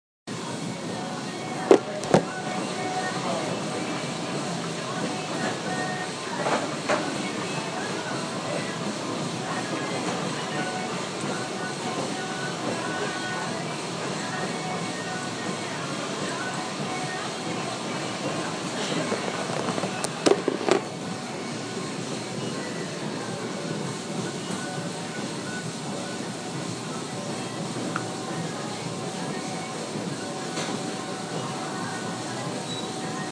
Field Recording 2
Hofstra University’s Fitness Center
Music over speakers, elliptical and other exercise machinery being used, the clunk of a machine being looked at